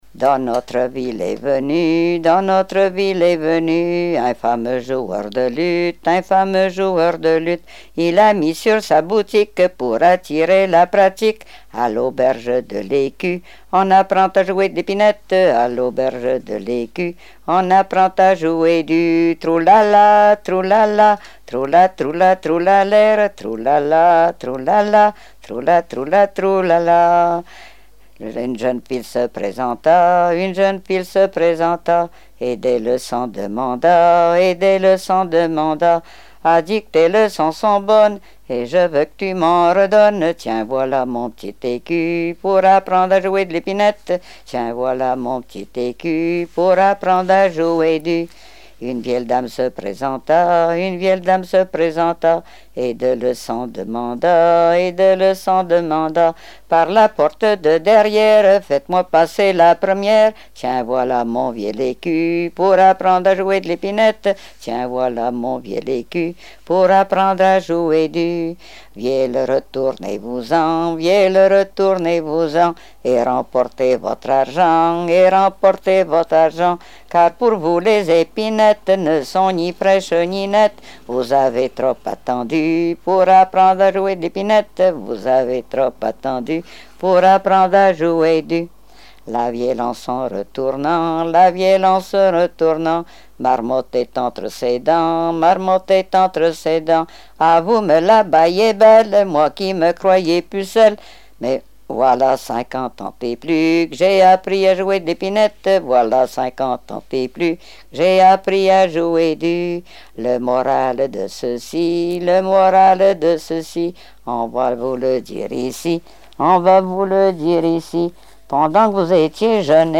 Répertoire de chansons traditionnelles et populaires
Pièce musicale inédite